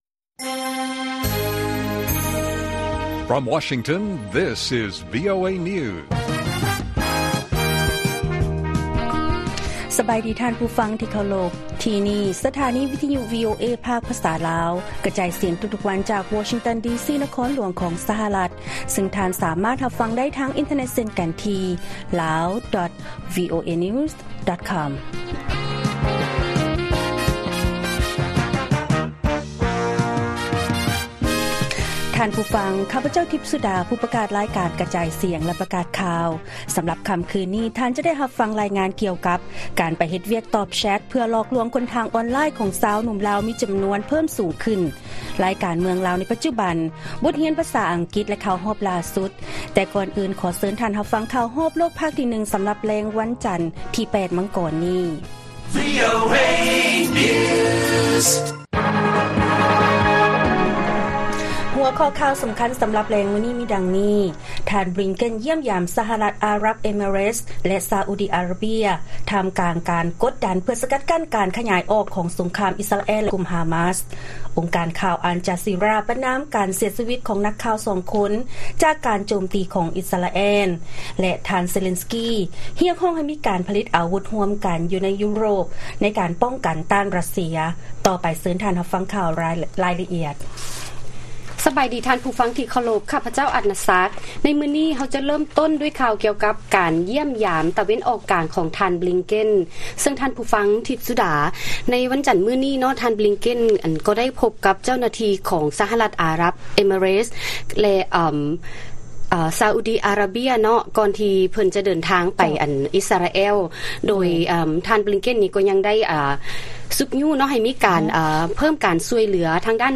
ລາຍການກະຈາຍສຽງຂອງວີໂອເອ ລາວ: ທ່ານບລິງເກັນ ຢ້ຽມຢາມ ສະຫະລັດອາຣັບເອເມີເຣັສ ແລະ ຊາອູດອາຣາເບຍ ທ່າມກາງການກົດດັນເພື່ອສະກັດກັ້ນການຂະຫຍາຍສົງຄາມ